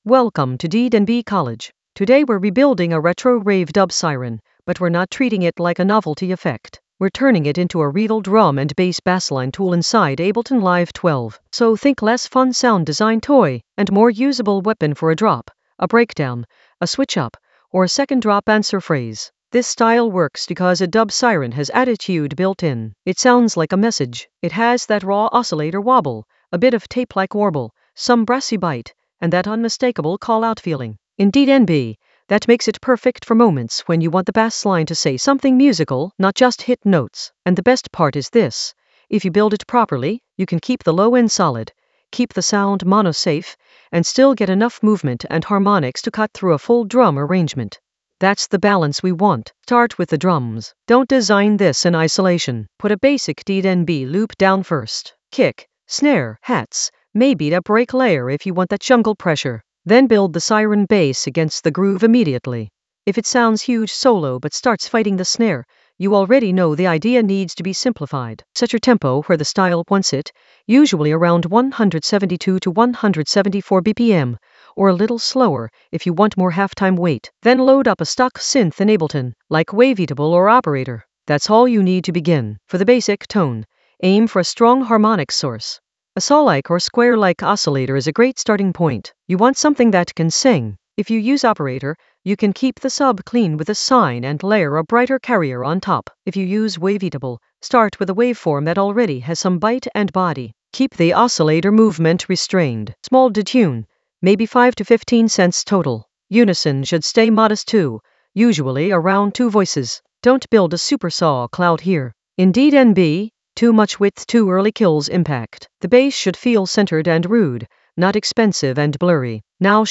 An AI-generated intermediate Ableton lesson focused on Retro Rave approach: a dub siren framework rebuild in Ableton Live 12 in the Basslines area of drum and bass production.
Narrated lesson audio
The voice track includes the tutorial plus extra teacher commentary.